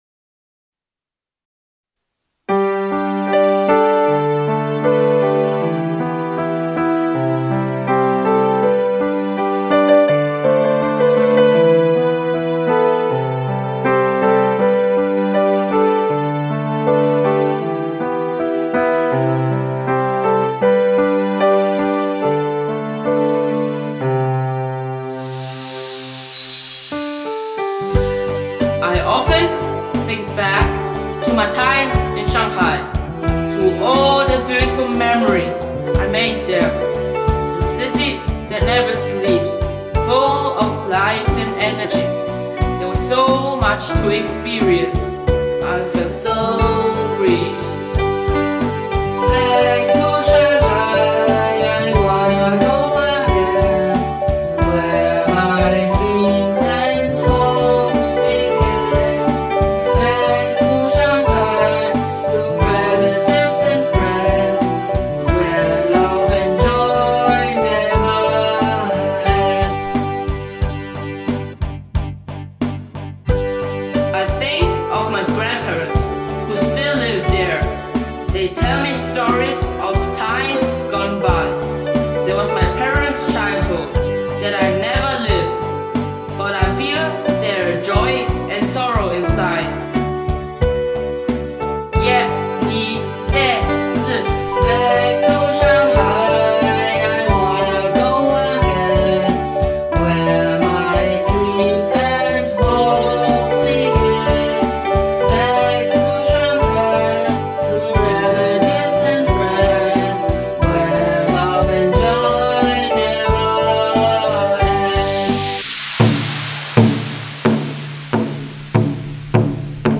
Holiday song